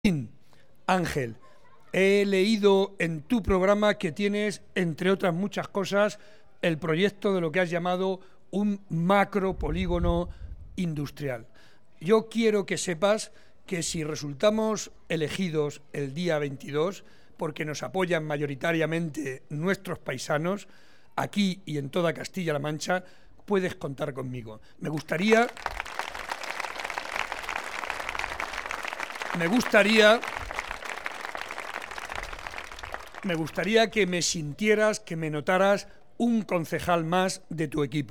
en el mitin que ha ofrecido a más de 200 vecinos en la Avenida del Riato